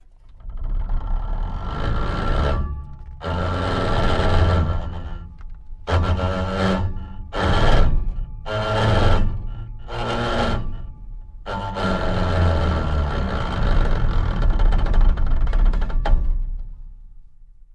Car Transmission Manual; Starts Rolling And Goes Steady With Very Bad Gear Grinds, Winds Down To Stop At End, No Motor, Interior Perspective 4x